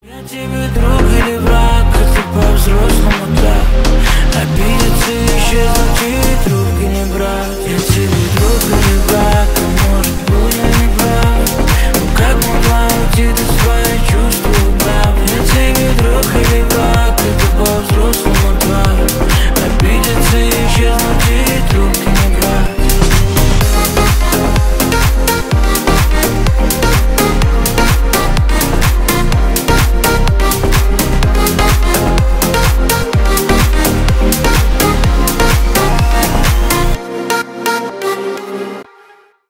Ремикс
клубные # грустные